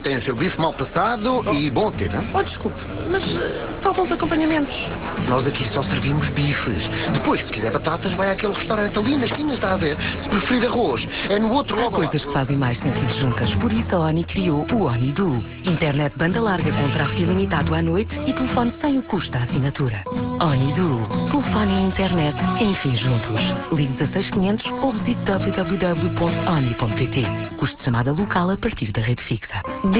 ...no passado dia 21 de Setembro estreou uma campanha da ONI a divulgar um novo serviço (internet e telefone). Esta campanha passa na RFM e TSF e teve 3 spots diferentes (